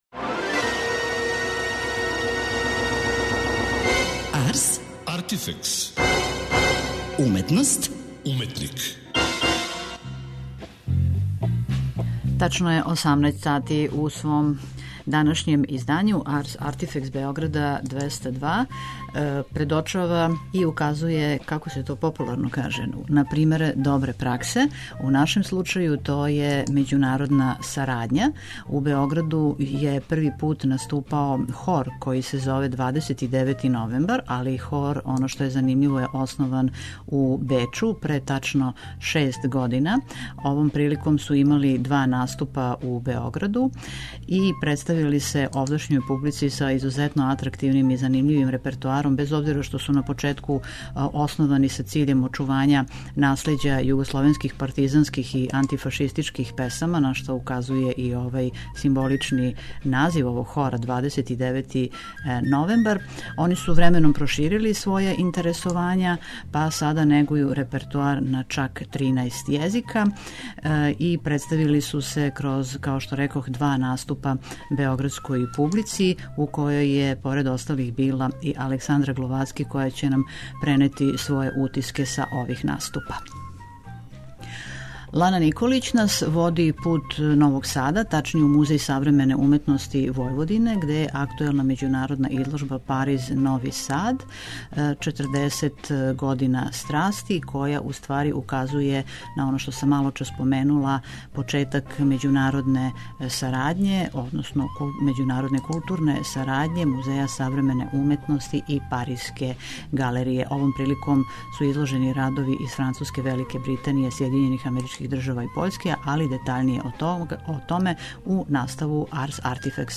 У данашњој емисији, у славу Рада и његовог празника представљамо Бечки хор '29. новембар'.